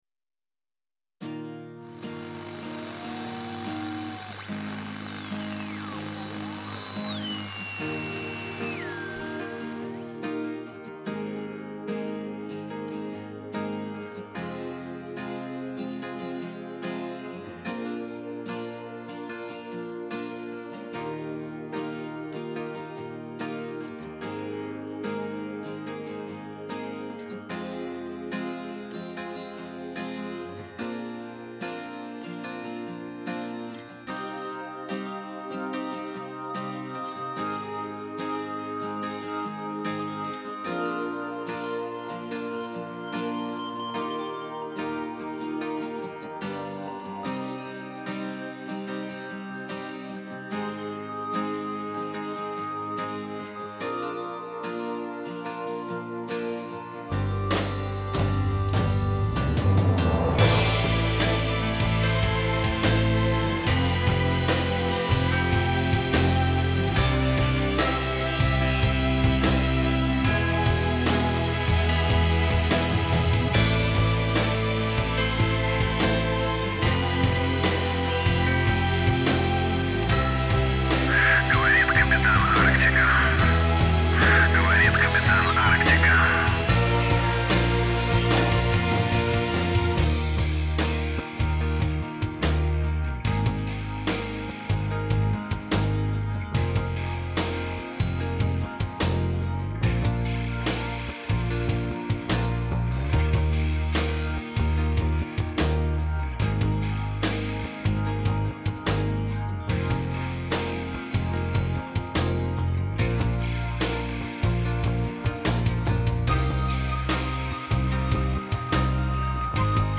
Пойте караоке